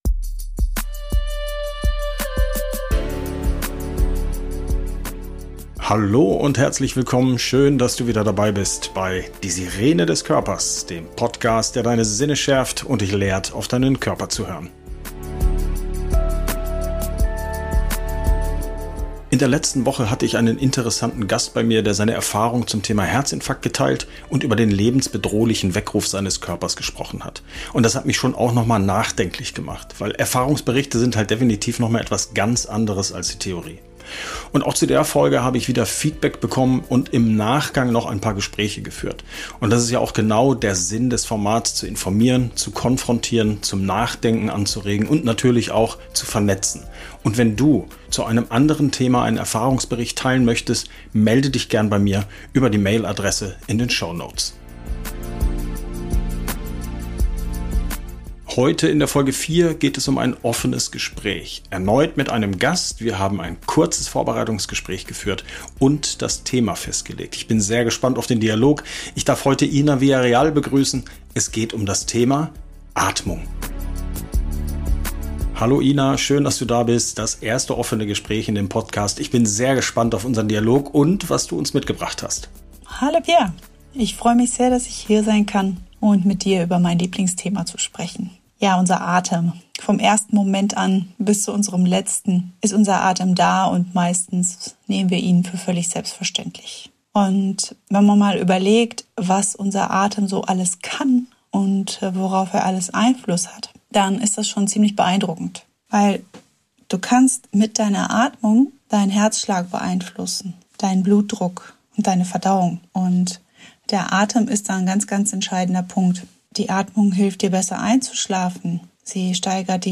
Begleite uns auf eine tiefgründigen Reise durch ein offenes Gespräch mit unserem besonderen Gast, während wir in die Essenz der Atmung eintauchen. Von Atemtechniken bis hin zu persönlichen Erfahrungen erkunden wir gemeinsam die transformative Kraft für Körper und Geist.